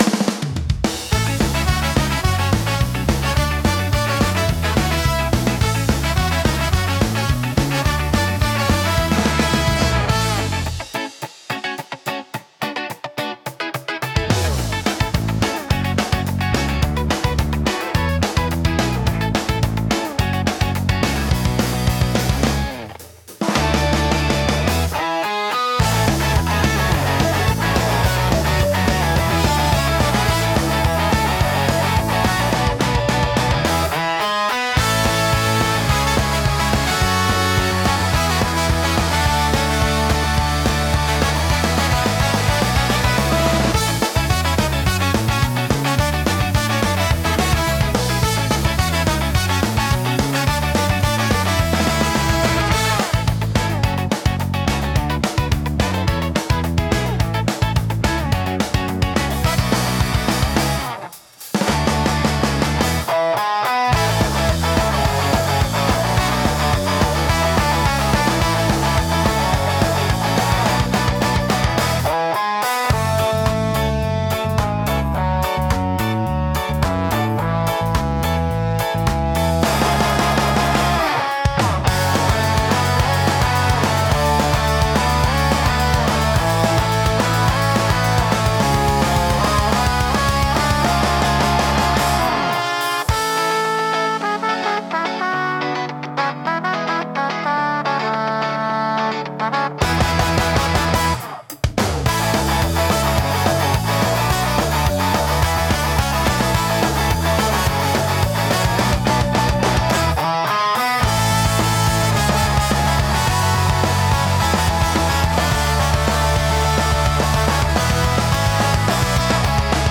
元気で勢いのあるサウンドが聴く人の興奮を高め、活気溢れる空間を作り出します。